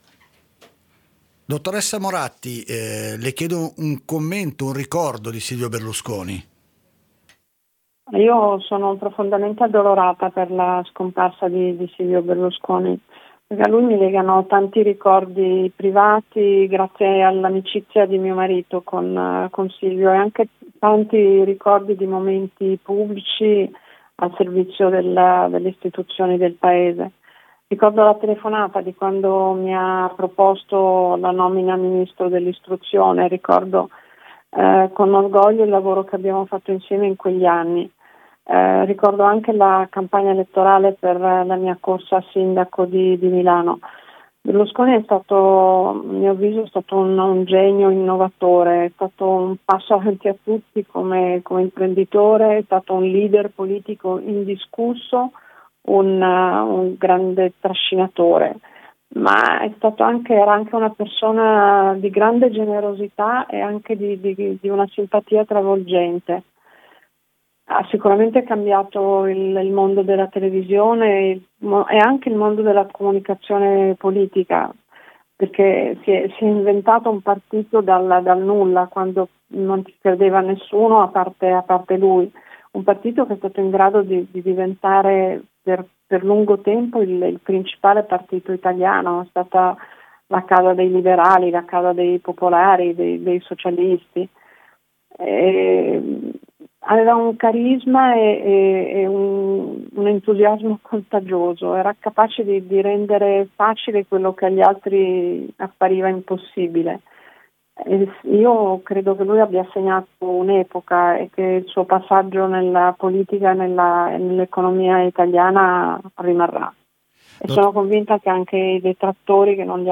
La dichiarazione di Letizia Moratti